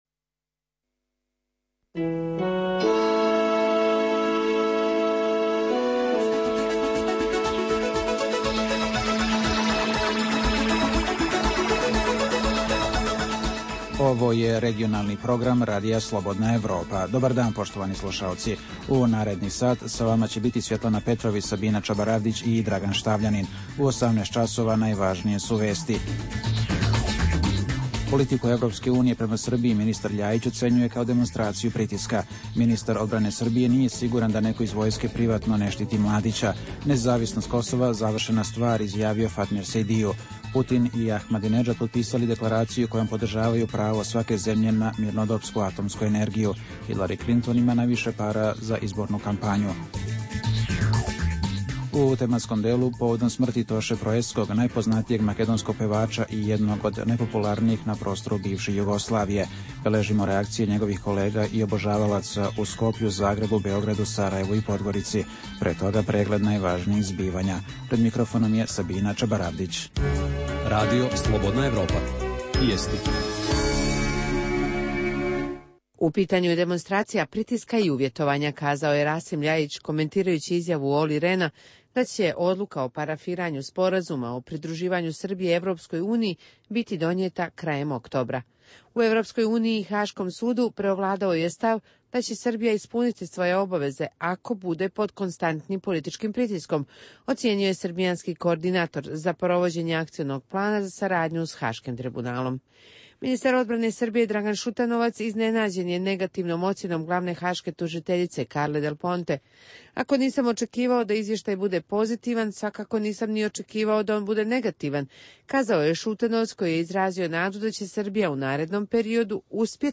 U regionalnoj emisiji Radija Slobodna Evropa u utorak, 16. oktobra u 18.00 beležimo: *Reakcije u Skoplju, Zagrebu, Beogradu, Sarajevu i Podgorici na smrt poznatog makedonskog pevača Toše Proeskog. Čućete izjave njegovih kolega, ostalih javnih ličnosti kao i građana.